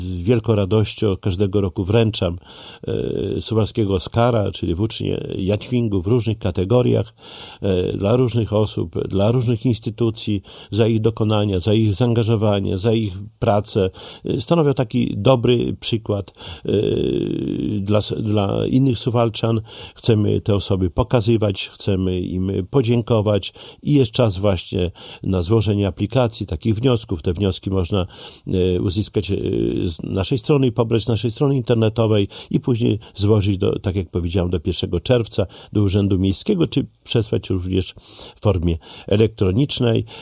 Szczegóły przedstawił Czesław Renkiewicz, prezydent miasta.